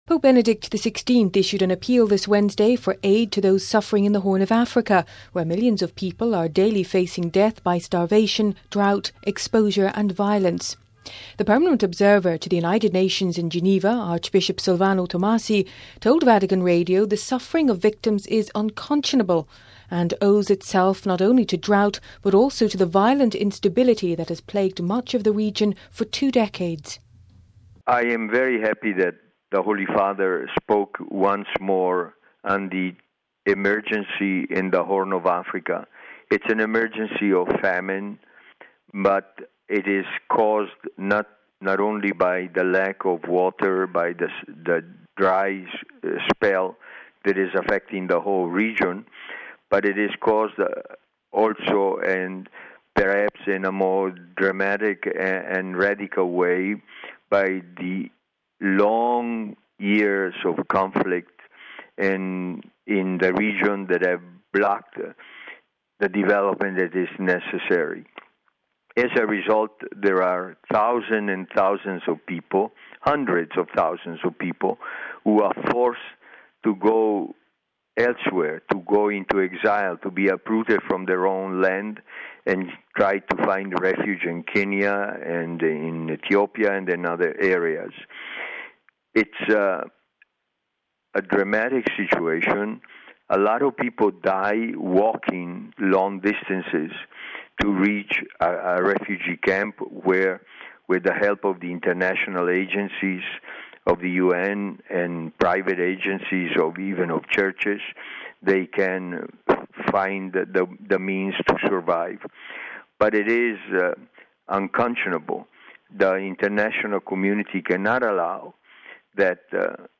Pope Benedict XVI issued an appeal this Wednesday for aid to those suffering in the Horn or Africa, where millions of people are daily facing death by starvation, drought, exposure and violence. The Permanent Observer to the United Nations in Geneva, Archbishop Silvano Tomasi told Vatican Radio the suffering of victims is "unconscionable," and owes itself not only to drought, but also to the violent instability that has palgued much of the region for two decades.